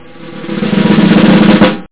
PSION CD 2 home *** CD-ROM | disk | FTP | other *** search / PSION CD 2 / PsionCDVol2.iso / Wavs / FLOURISH ( .mp3 ) < prev next > Psion Voice | 1998-08-27 | 15KB | 1 channel | 8,000 sample rate | 2 seconds
FLOURISH.mp3